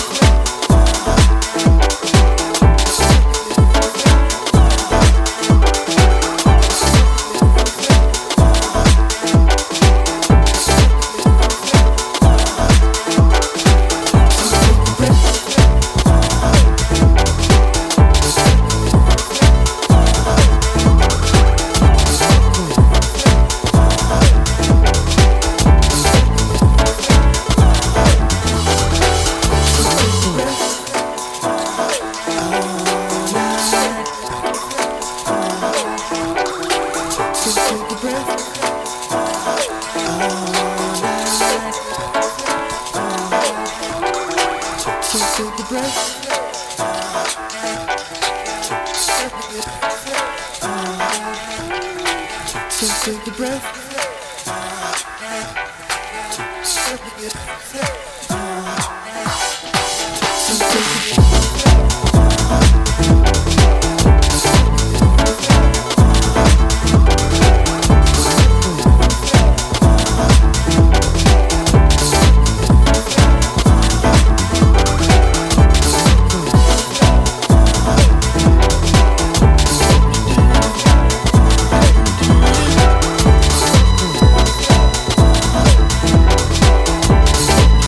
Pure dancefloor joy is guaranteed with this release.
very organic sounding, warm, soul-driven jazzy house songs